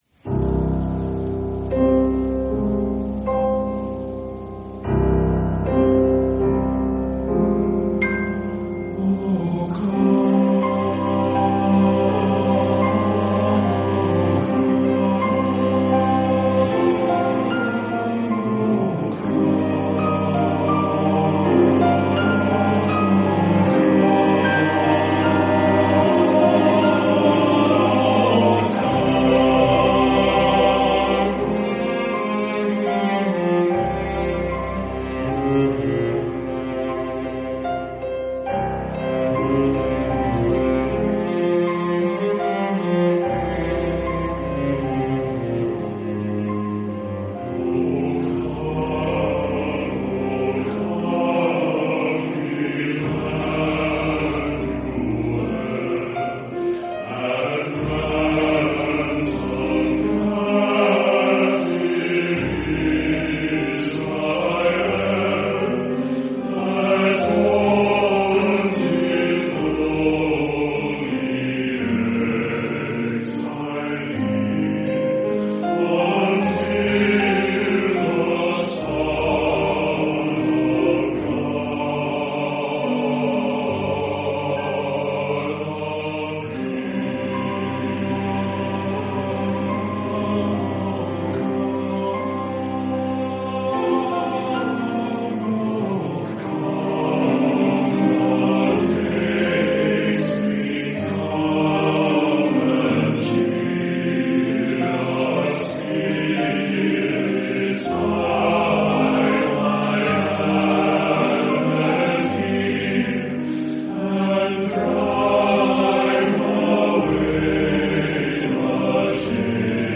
original fifteenth-century Advent hymn